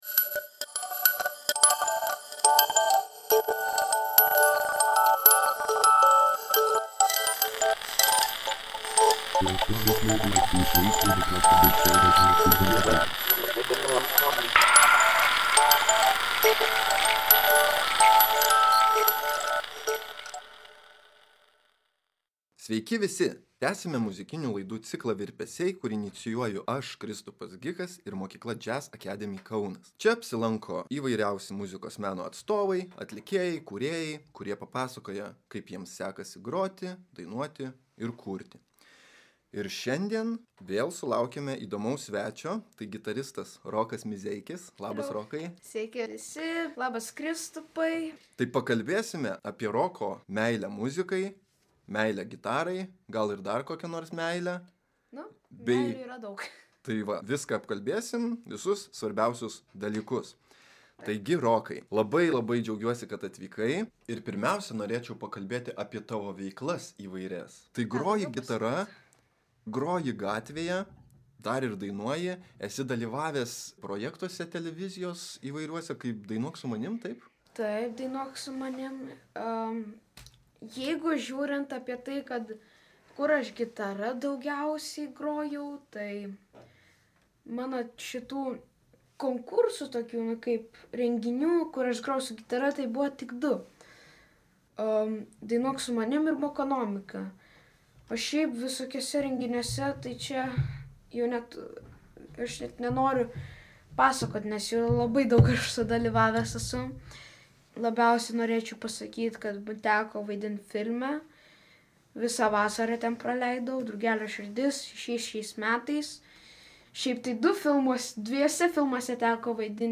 Pokalbis
Podcast’e panaudotų muzikos fragmentų pilnus kūrinius galite pasiklausyti paspaudę ant nuorodų: